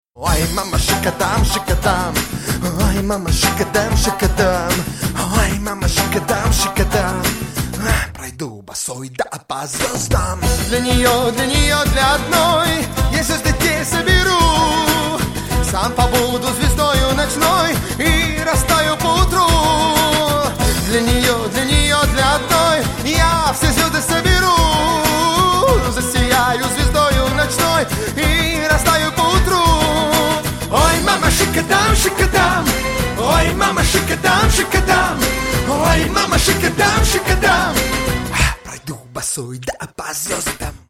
Рингтоны Дискотека 90х
Поп Рингтоны